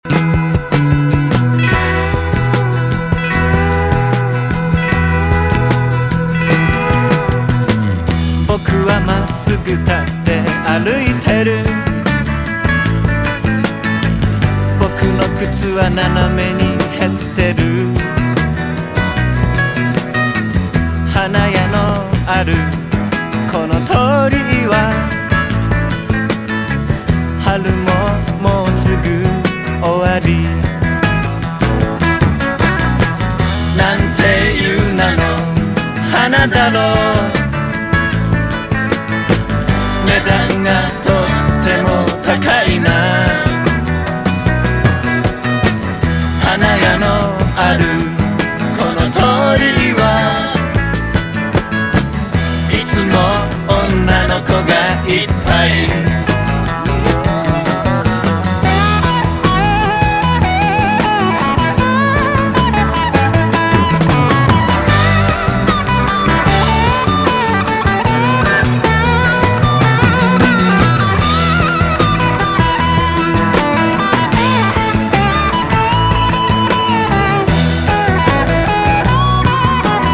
COUNTRY ROCK
スウィートなカントリー・ロック・クリスマス！